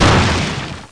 1 channel
boxcrack.mp3